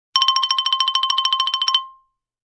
Descarga de Sonidos mp3 Gratis: xilofono 18.